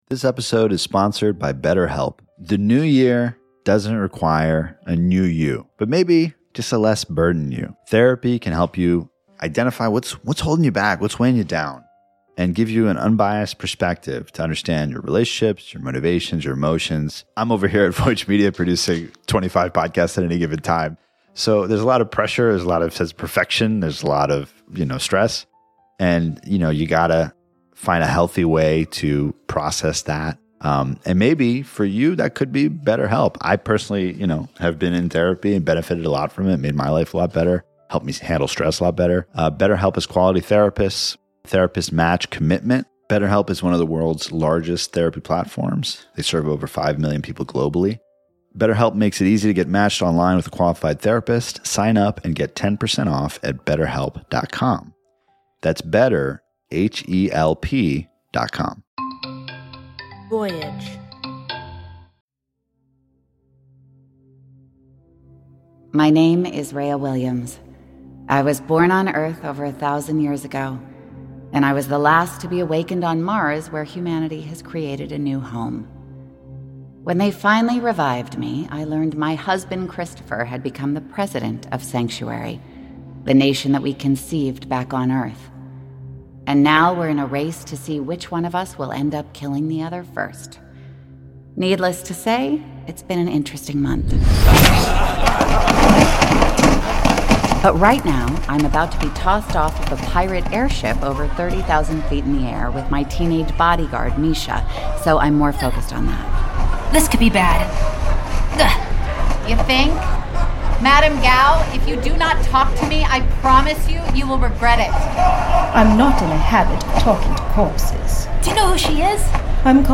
Starring Lily Rabe, as Reya.